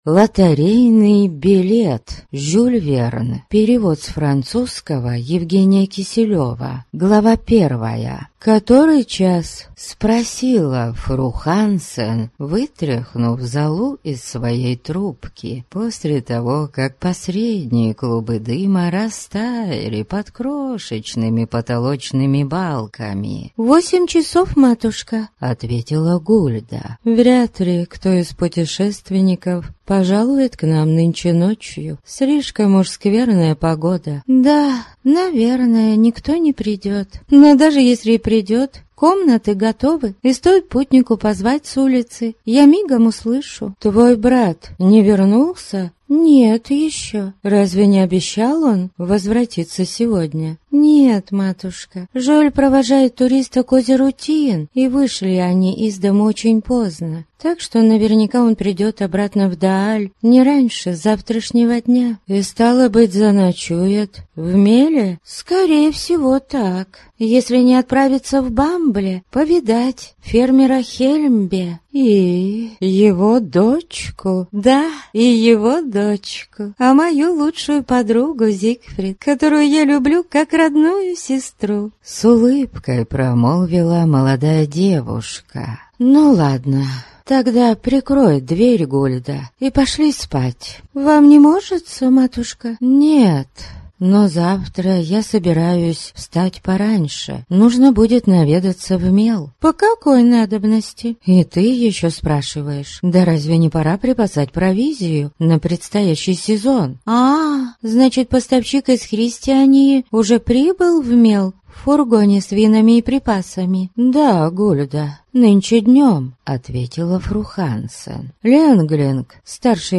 Аудиокнига Лотерейный билет | Библиотека аудиокниг